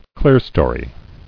[clear·sto·ry]